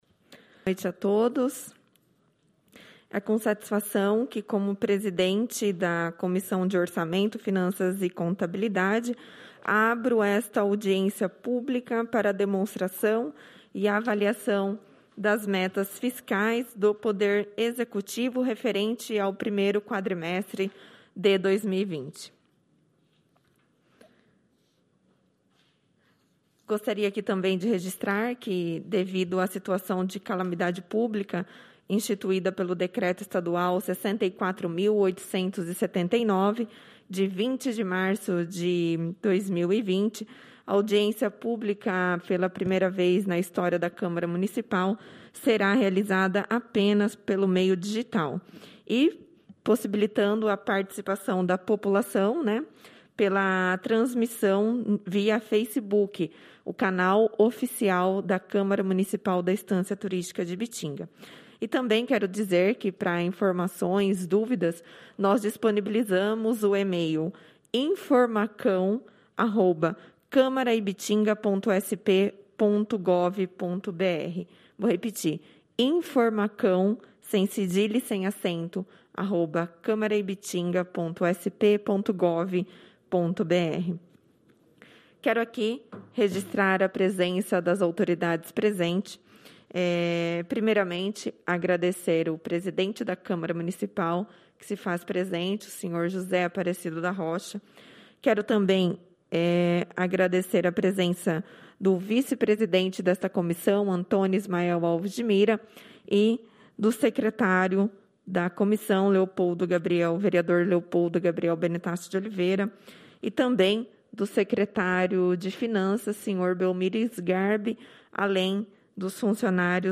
audiência pública de 29/05/2020